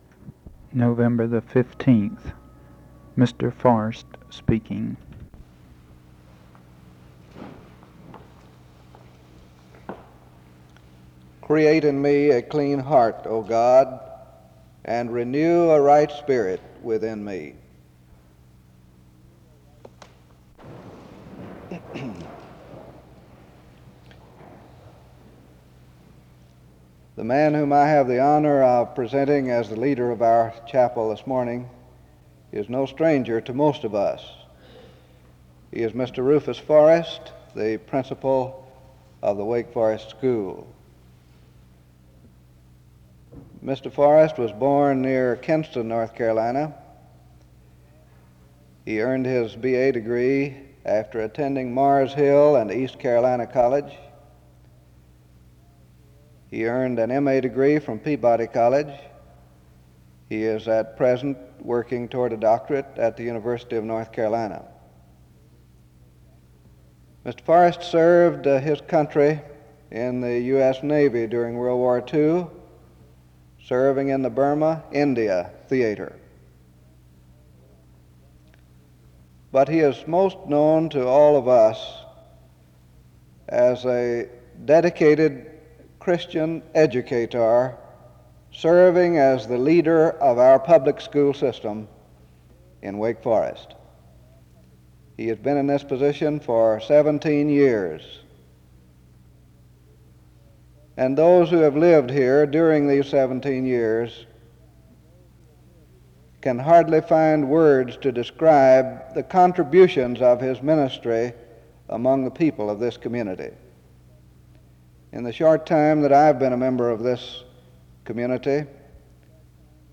The service begins with a lengthy introduction and prayer from 0:00-8:44.
SEBTS Chapel and Special Event Recordings SEBTS Chapel and Special Event Recordings